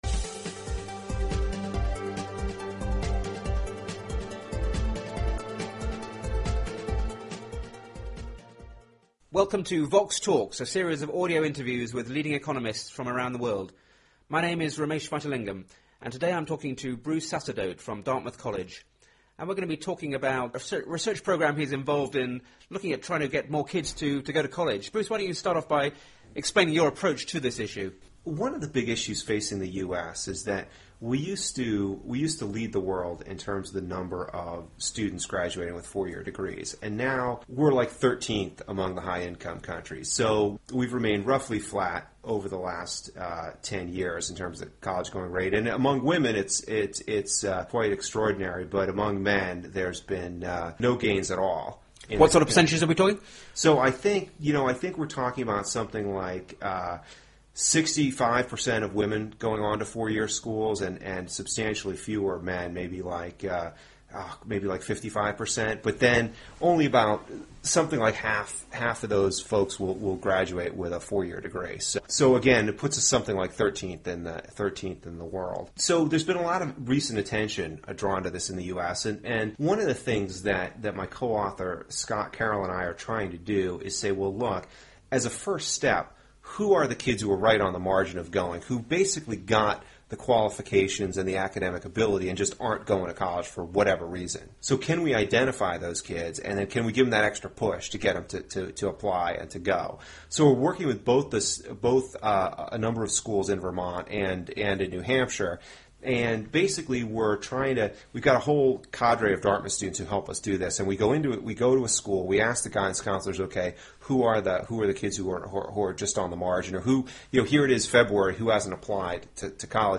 They discuss the returns to college education, and the role of guidance counselling. The interview was recorded at the Centre for Market and Public Organisation in Bristol (UK) in February 2009.